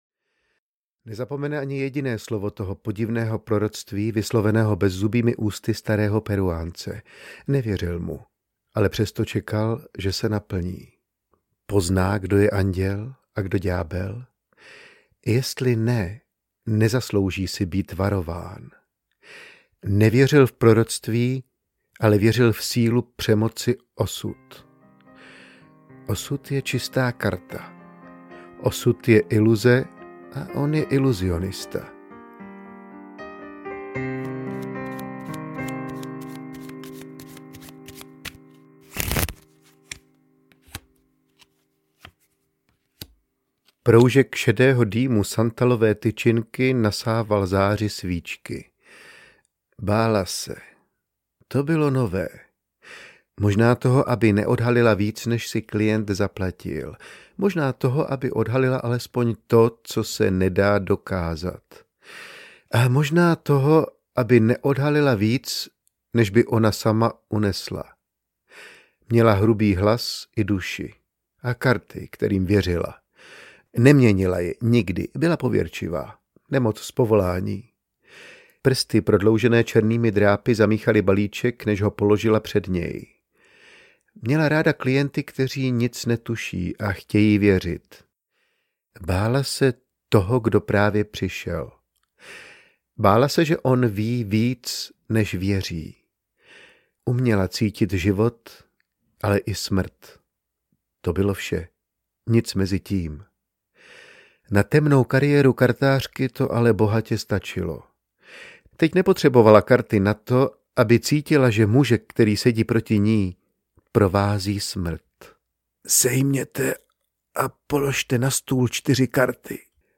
Ukázka z knihy
Klavírní doprovod
daltonuv-posledni-trik-audiokniha